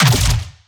Plasma Rifle
LASRGun_Plasma Rifle Fire_02_SFRMS_SCIWPNS.wav